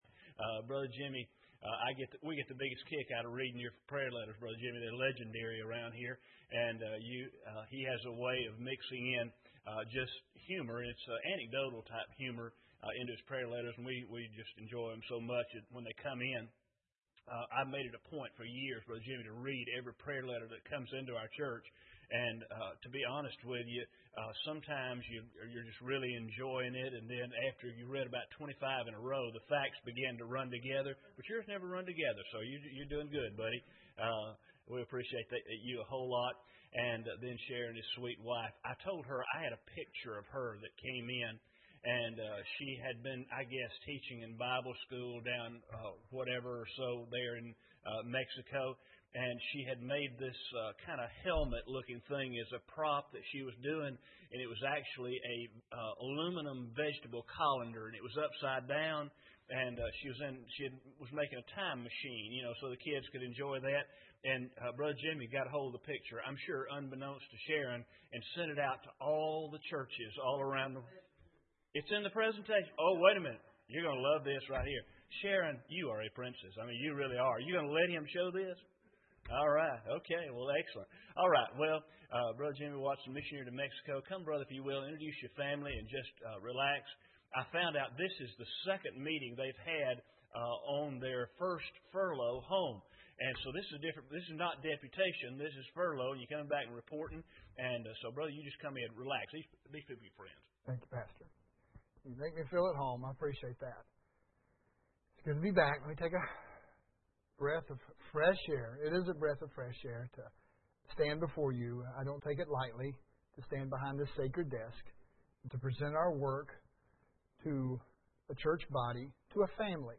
Missionary Report
General Service Type: Sunday Evening Preacher